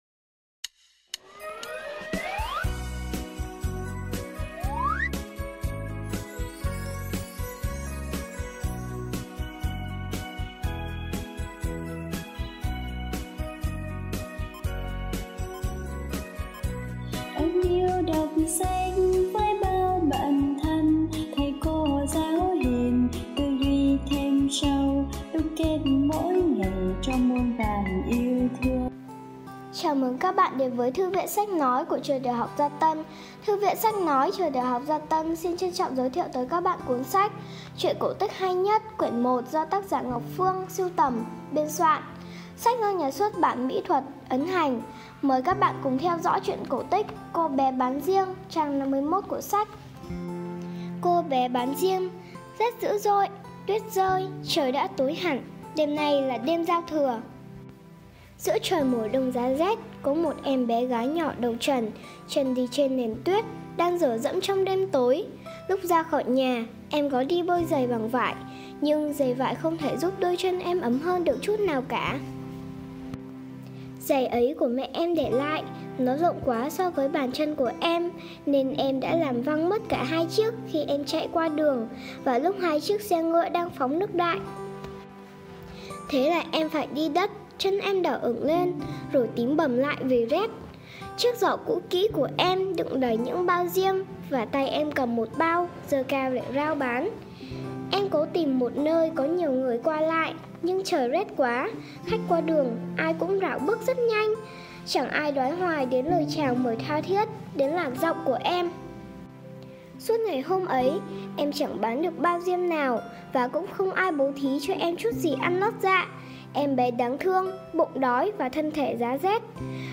Sách nói | Truyện cổ tích hay nhất Quyển 1 - Cô bé bán diêm - năm học 2022 - 2023